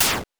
explosion_12.wav